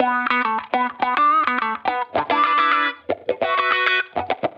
Index of /musicradar/sampled-funk-soul-samples/105bpm/Guitar
SSF_StratGuitarProc1_105B.wav